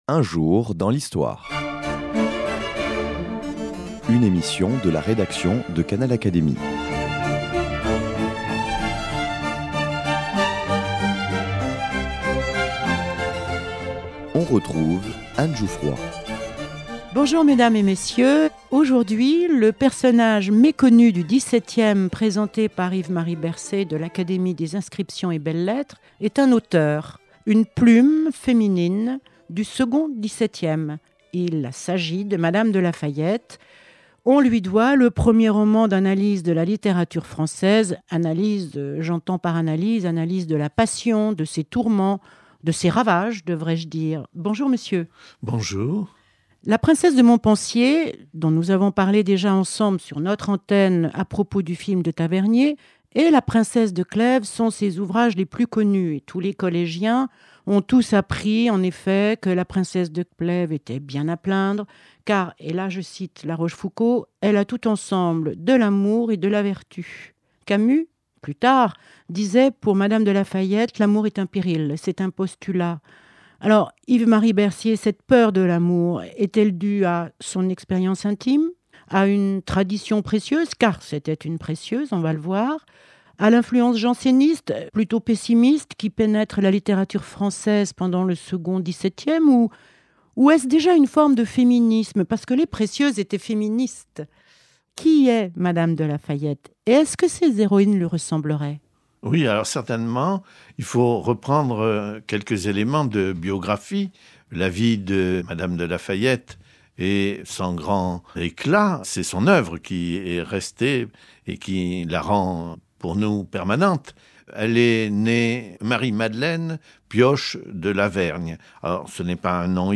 Yves-Marie Bercé, membre de l’Académie des inscriptions et belles-lettres, évoque Mme de la Fayette (1634-1693) et ses « Questions d’Amour ». et La Princesse de Montpensier furent les premiers romans d’analyse de la littérature française et restent des modèles du genre.